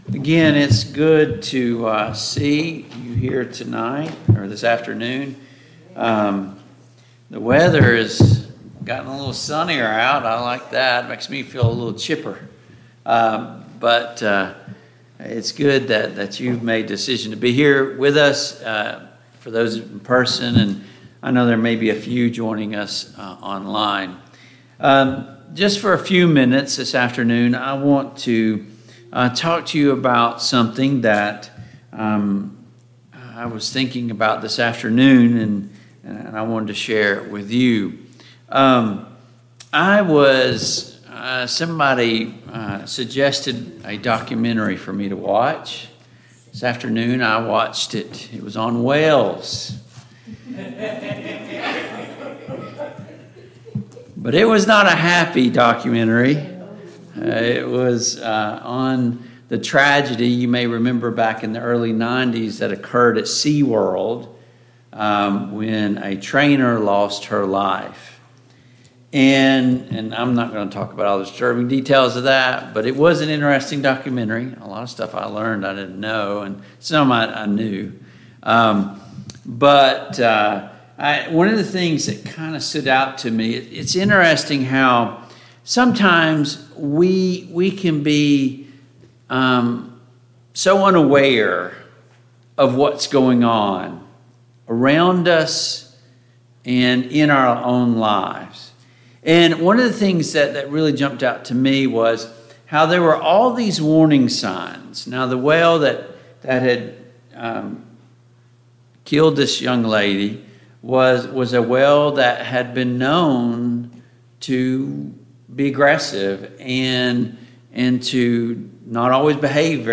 Preacher
Service Type: PM Worship Topics: Falling Away , Falling from Grace « 6.